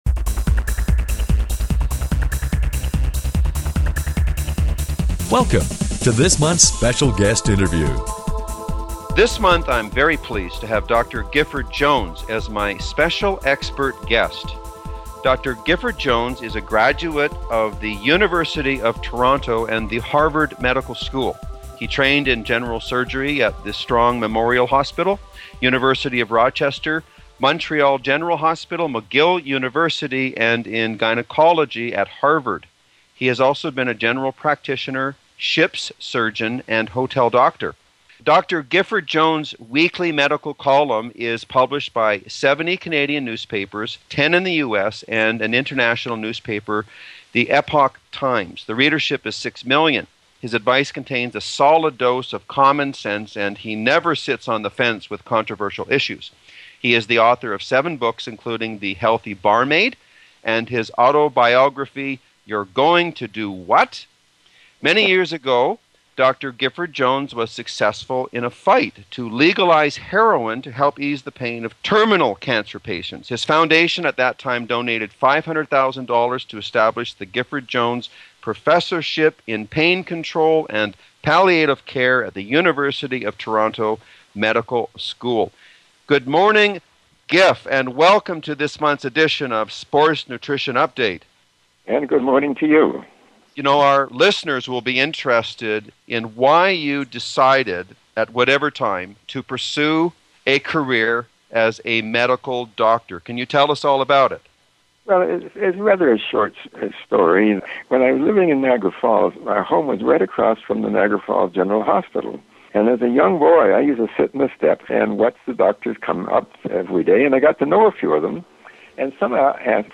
Special Guest Interview Volume 13 Number 5 V13N5c - Guest Dr. Gifford-Jones is a graduate of the University of Toronto and The Harvard Medical School.